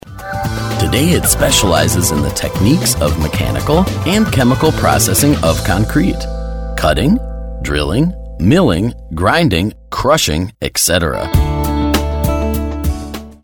Native speaker Male 30-50 lat
Rolling, vital mid-range voice.
Nagranie lektorskie